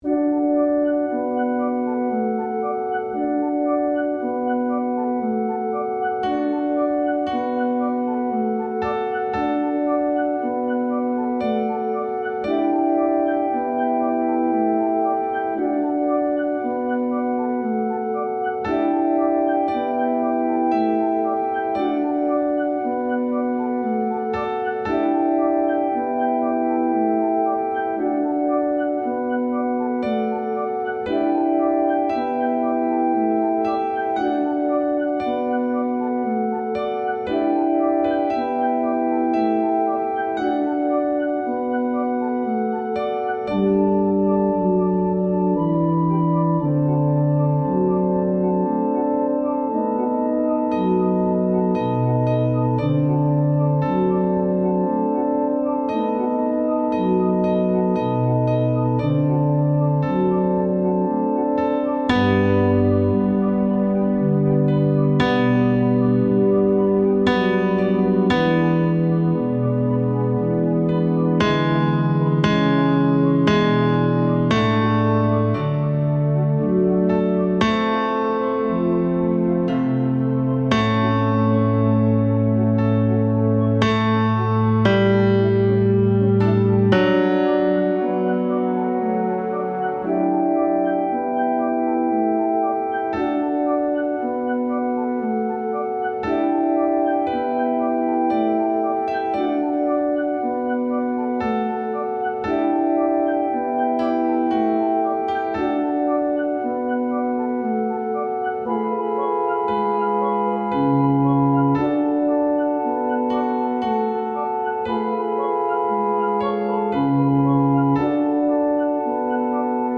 Tenor II
Mp3 Música